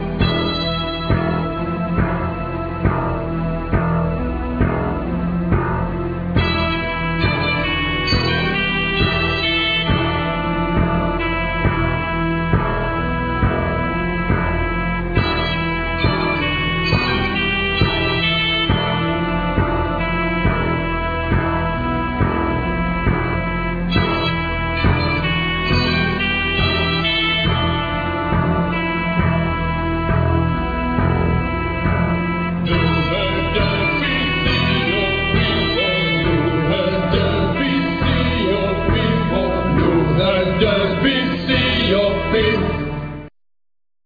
Accordion,Guitar,Vocals
Clarinet,Guitar,Vocals
Drums
Baritone&Tennor Sax